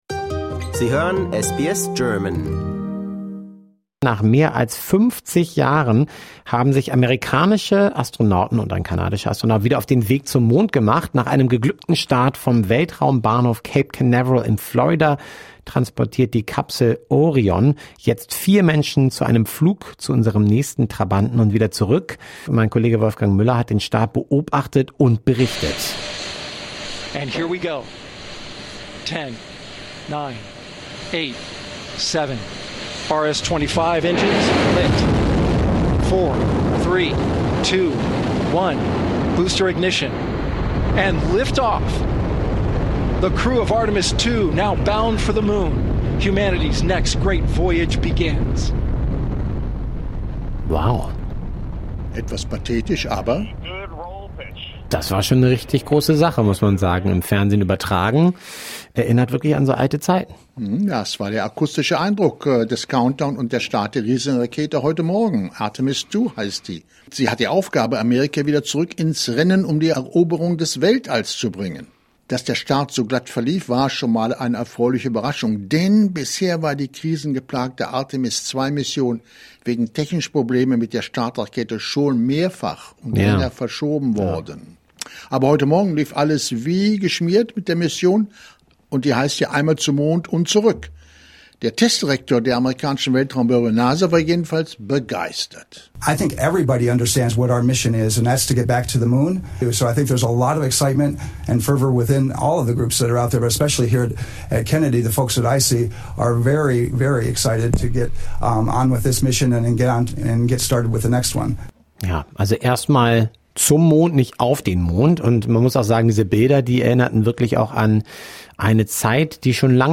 For more stories, interviews, and news from SBS German, discover our podcast collection here.